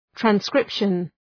Προφορά
{træn’skrıpʃən}